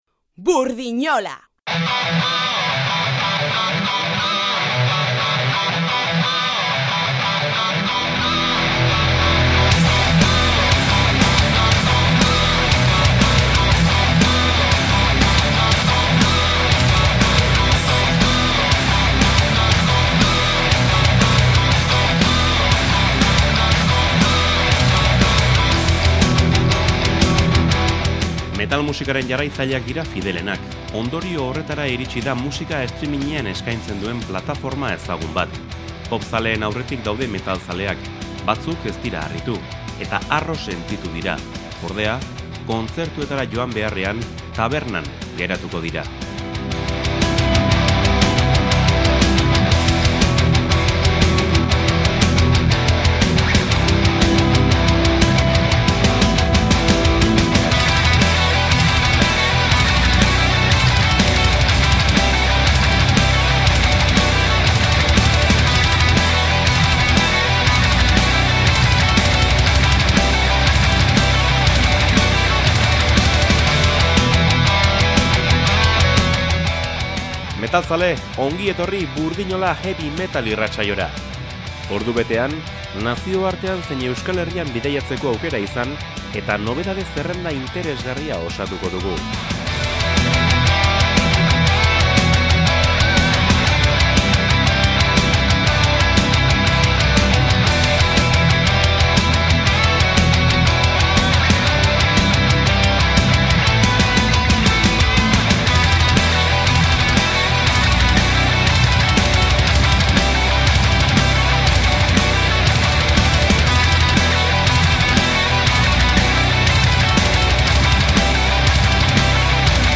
Ilunagoa eta gordinagoa izan da atzoko Burdinola heavy metal irratsaioa
Heavy Metal bilduma berri bat!